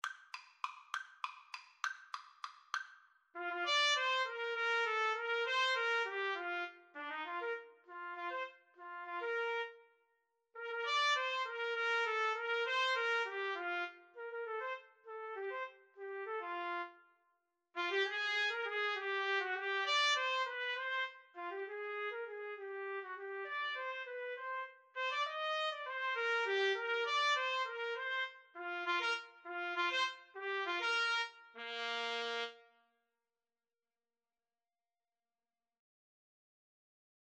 3/8 (View more 3/8 Music)
Bb major (Sounding Pitch) C major (Trumpet in Bb) (View more Bb major Music for Trumpet Duet )